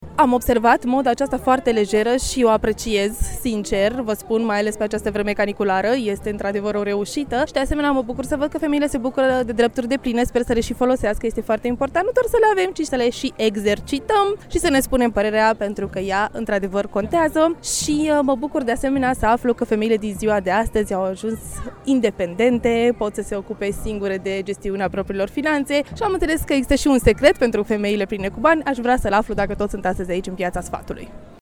Personajele istorice au revenit în  cadrul Retro Marktplatz, eveniment încă în desfășurare, și care rememorează atmosfera târgurilor Brașovului de odinioară.